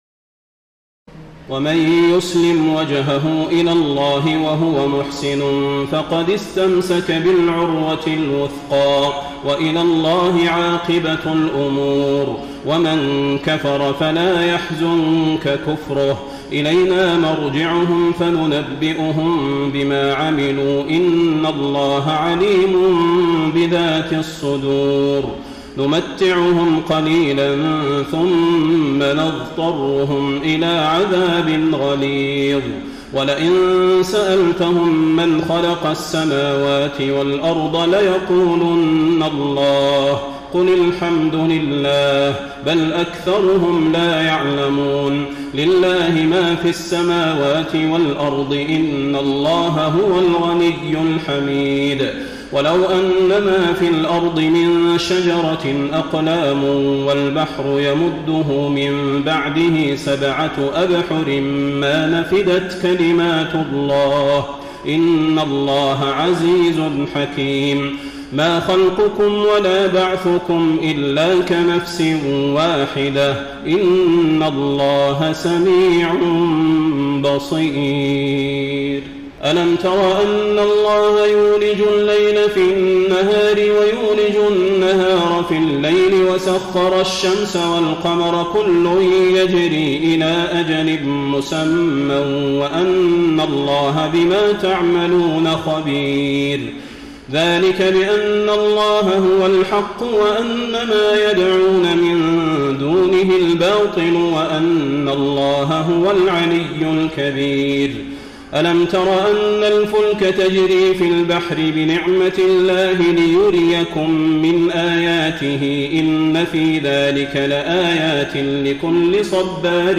تراويح الليلة العشرون رمضان 1433هـ من سور لقمان (22-34) والسجدة و الأحزاب (1-31) Taraweeh 20 st night Ramadan 1433H from Surah Luqman and As-Sajda and Al-Ahzaab > تراويح الحرم النبوي عام 1433 🕌 > التراويح - تلاوات الحرمين